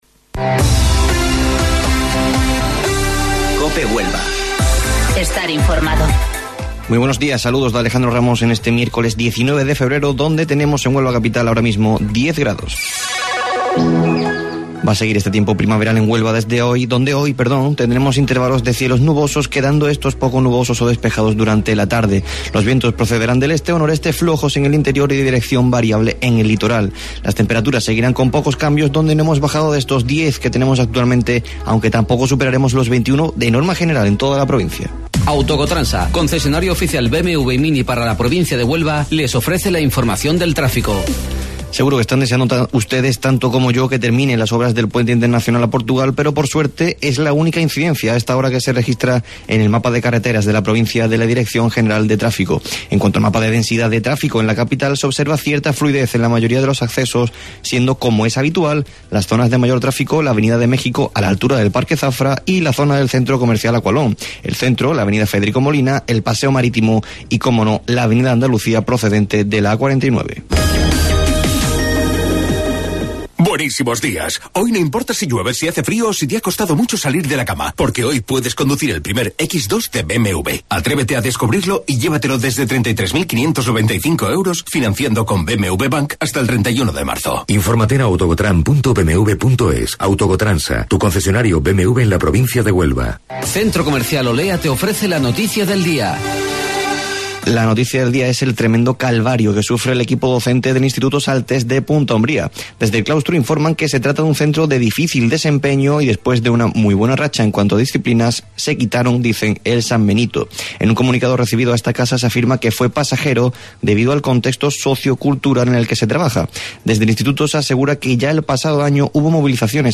AUDIO: Informativo Local 08:25 del 19 Febrero